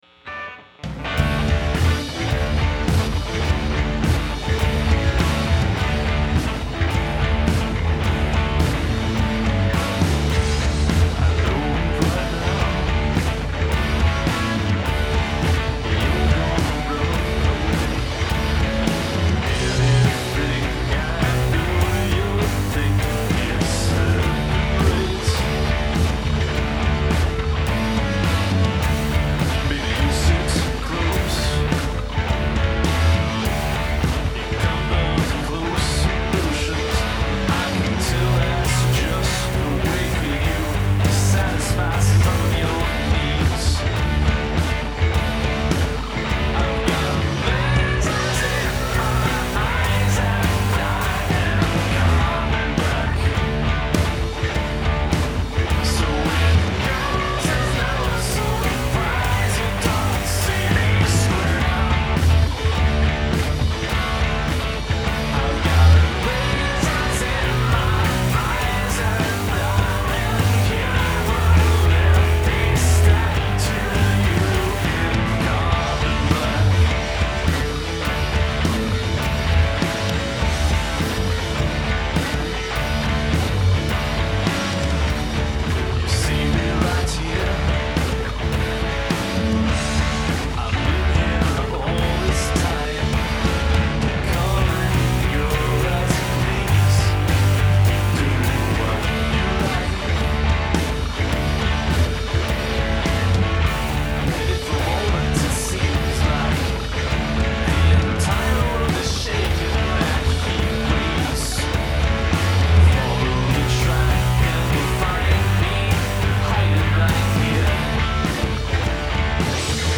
harsh, 80s metal sort of sound
drums, bass
I like this, though, because there's a certain swing to it. I like the vocal effects during the chorus too.
Thought the main vox were a bit low during the verses, but the high harmonies in the chorus were sweet.
They're sitting too deep in the mix.
Falsetto in the chorus is so good.